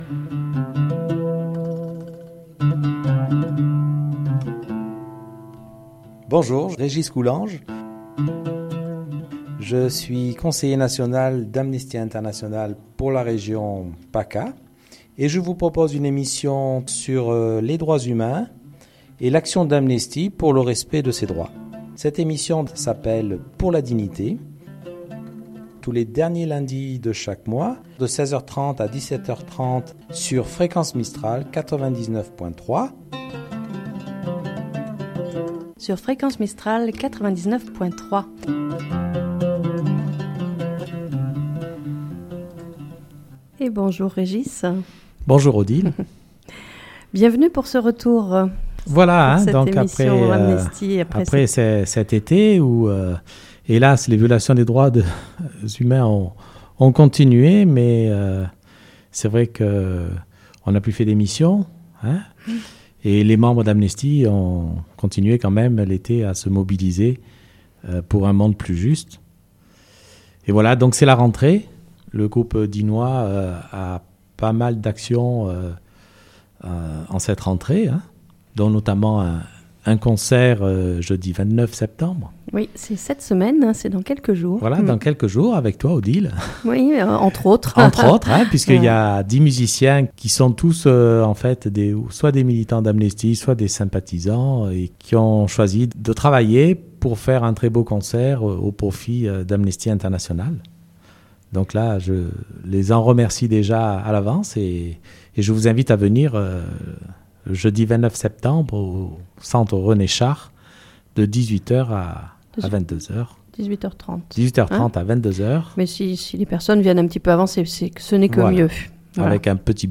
« Pour la Dignité » émission de Fréquence Mistral sur les Droits Humains chaque dernier lundi du mois à 16h30
Voici le Sommaire en sachant que cette émission sera agrémentée d’extraits musicaux de quelques artistes militants pour Amnesty, qui seront en concert ce Jeudi à Digne-les-B ains dès 18h30 au Centre Culturel :